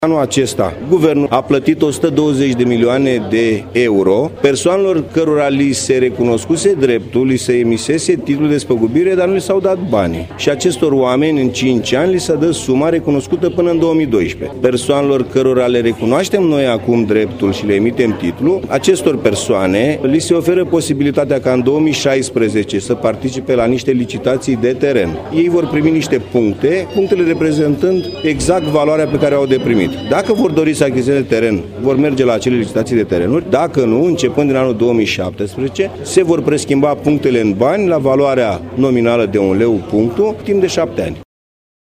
Guvernul a aprobat sumele, pe anul 2015, pentru despăgubiri pe legile retrocedărilor. Anunţul a fost făcut, vineri, la Arad, de preşedintele Autorităţii Naţionale pentru Restituirea Proprietăţilor, George Băeşu.